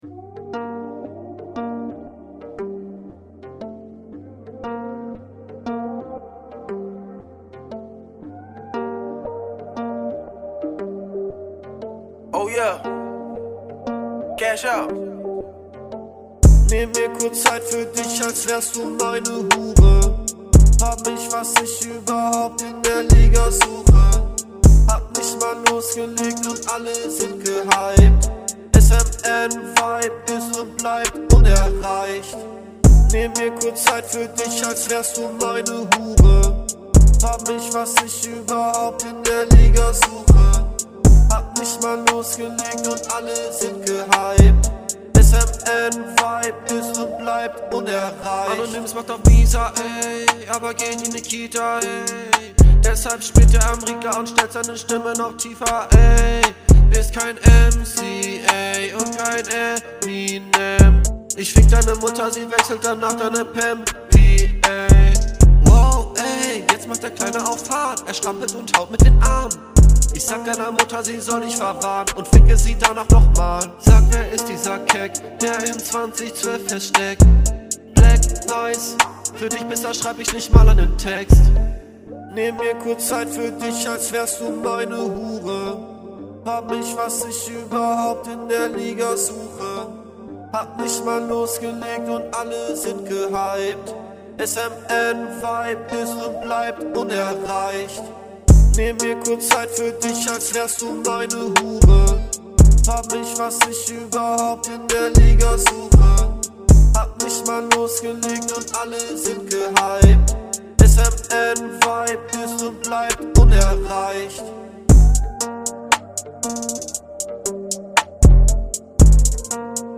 Flow: Stabiler Flow, hab jetzt nix schlechtes rausgehört.